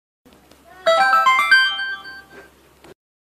1up sound
1up-sound.mp3